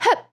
attack1.wav